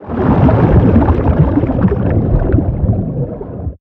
Sfx_creature_shadowleviathan_swimpatrol_04.ogg